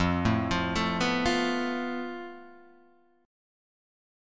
FMb5 chord